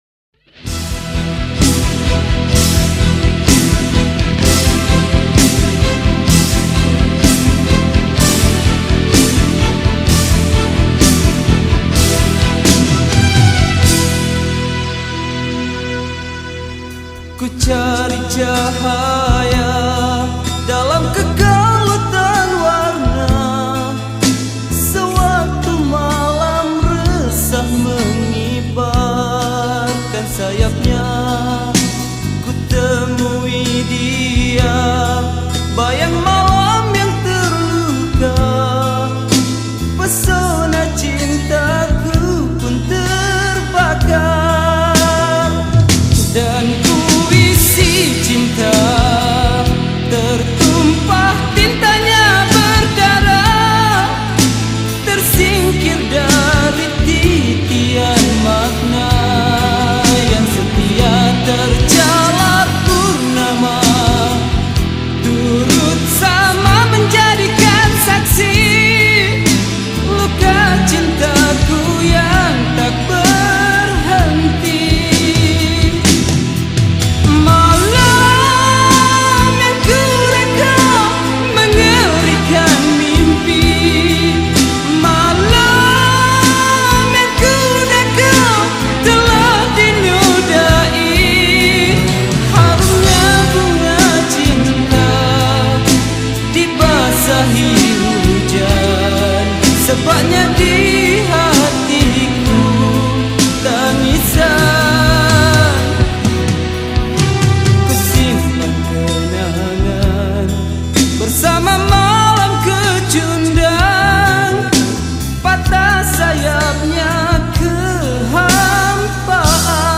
Malay Songs
Skor Angklung